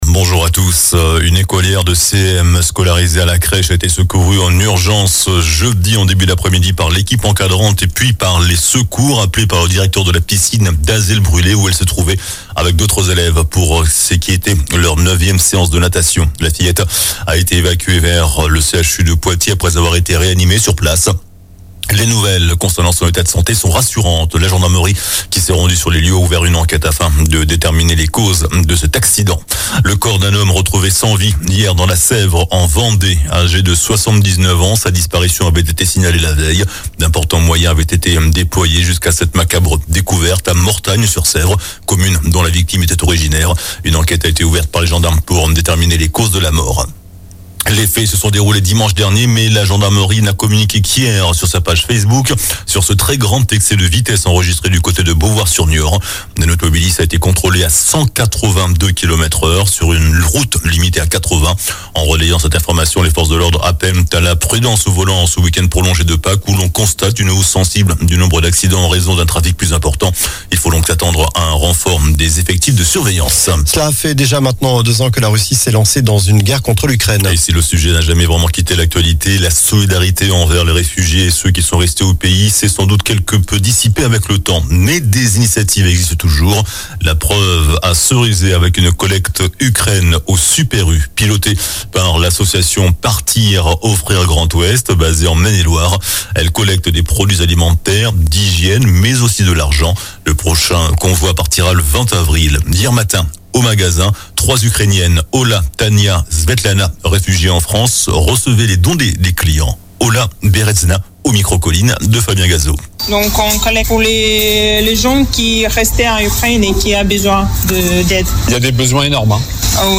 JOURNAL DU SAMEDI 30 MARS